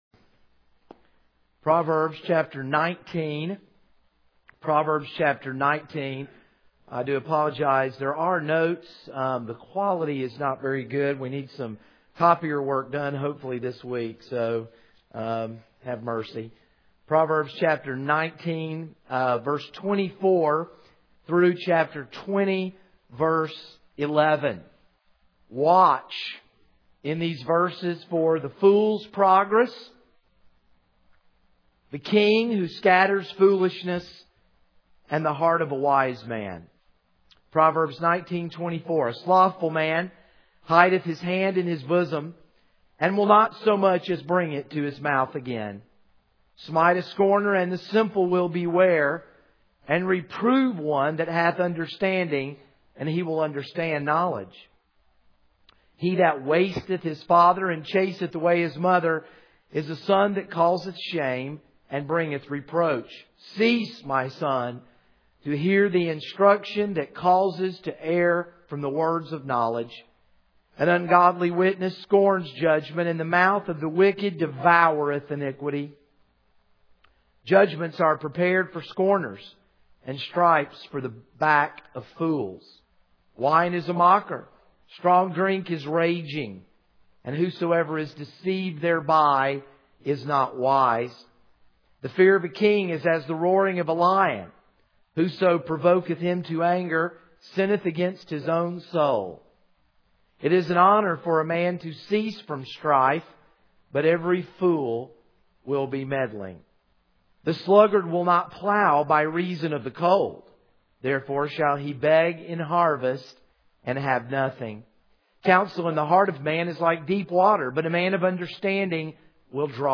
This is a sermon on Proverbs 19:24-20:11.